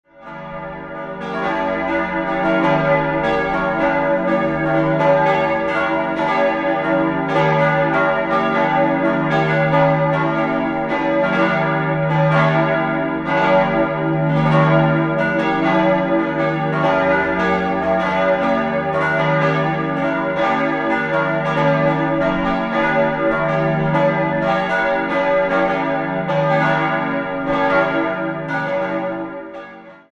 Verminderter Vierklang: cis'-e'-gis'-ais' Die Gussstahlglocken erklingen seit 1922 vom Turm der Pfarrkirche und wurden vom Bochumer Verein gegossen.